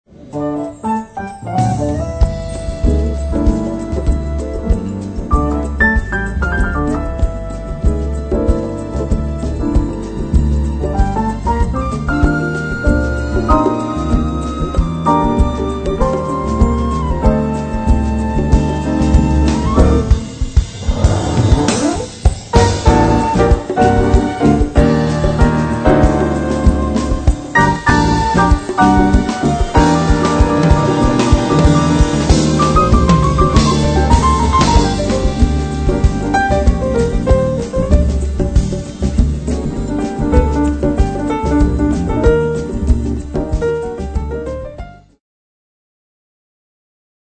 Płyta jest bardzo żywiołowa, wręcz taneczna.
pianisty